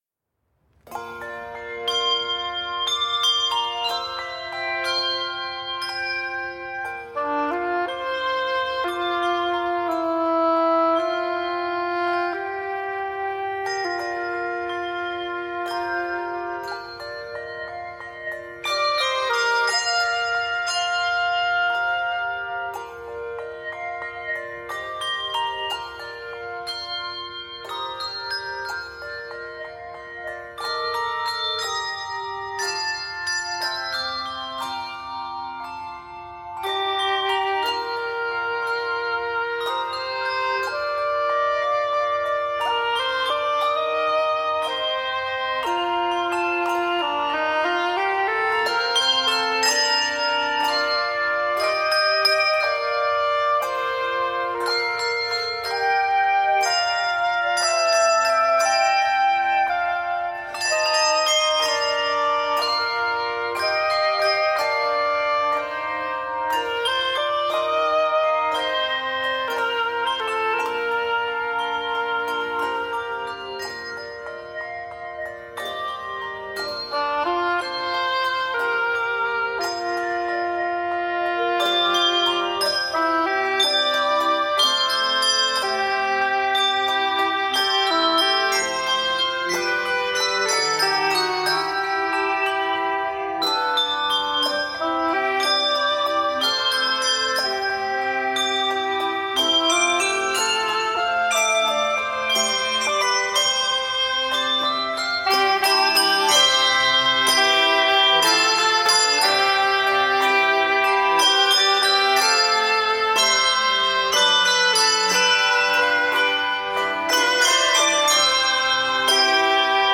Keys of Bb Major and C Major.